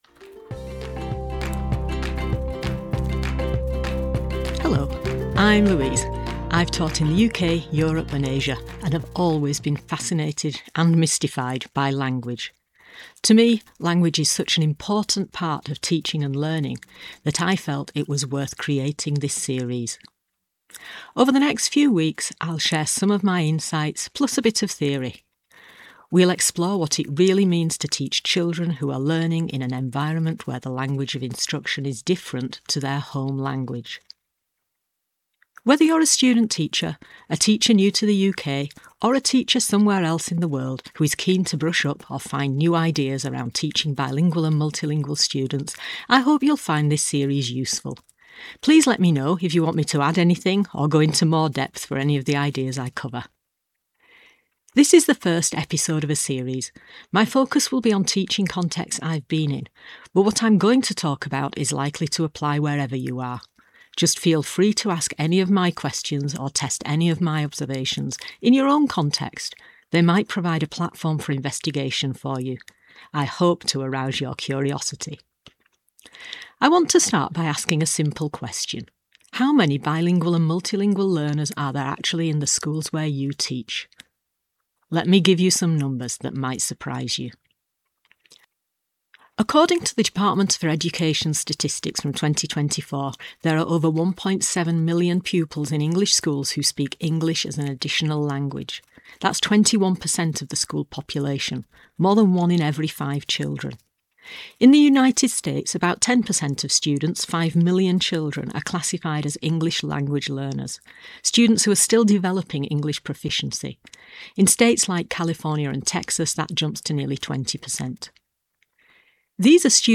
I’ve started a series of short talks on bilingualism, covering a blend of theory, practical ideas, and my own experiences living in different linguistic contexts and working with bilingual and multilingual students.
Final-Episode-Bilingualism-1-1-with-music.mp3